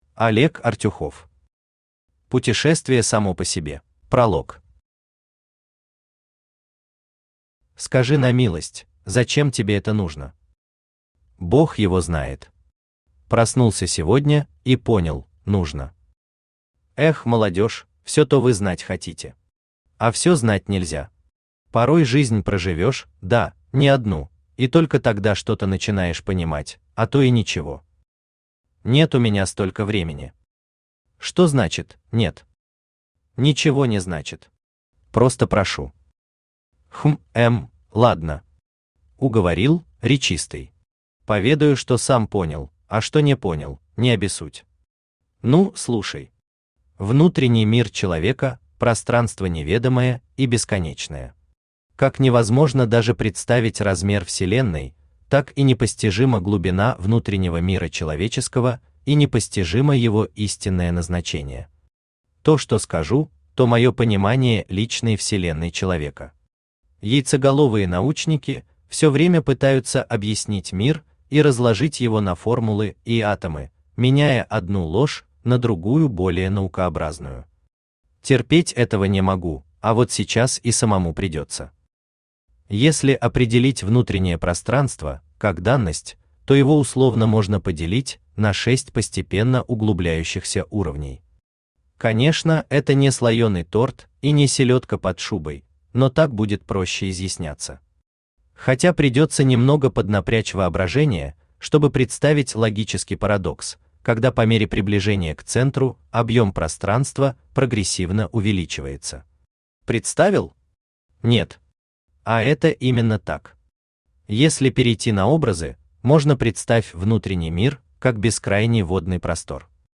Аудиокнига Путешествие само по себе | Библиотека аудиокниг
Aудиокнига Путешествие само по себе Автор Олег Артюхов Читает аудиокнигу Авточтец ЛитРес.